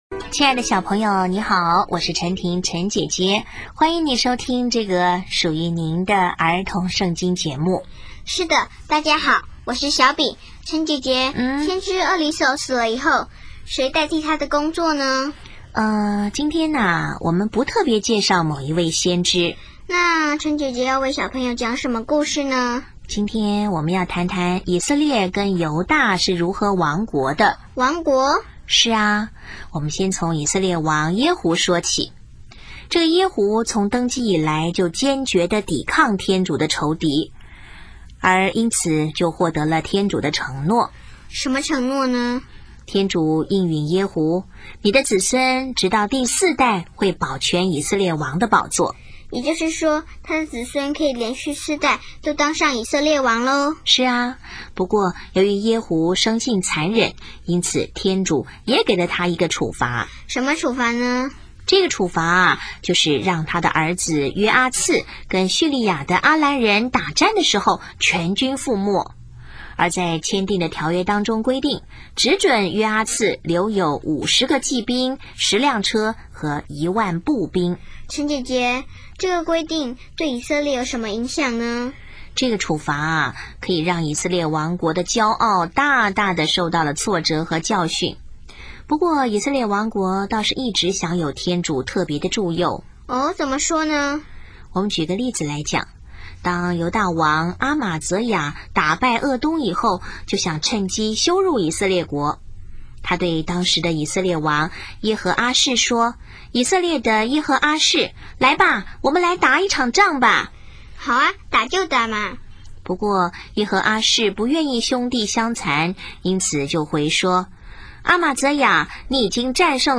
【儿童圣经故事】